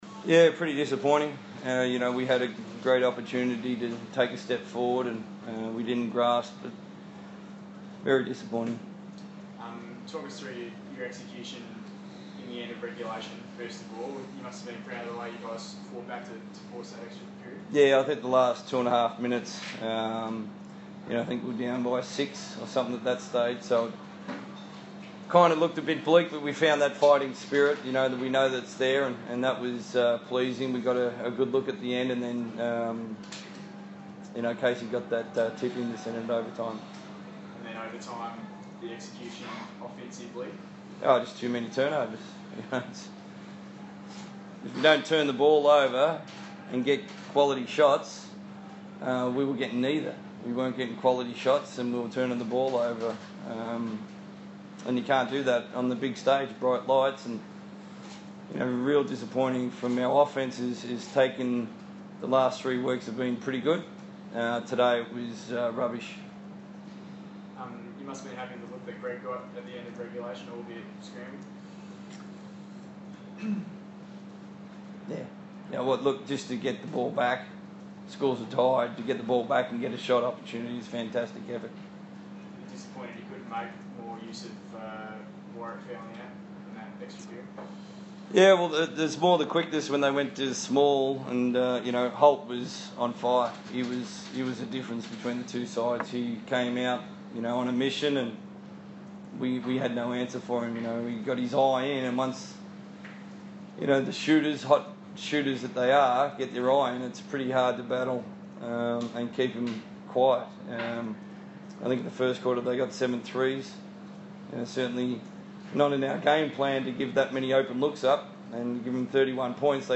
post-game press conference.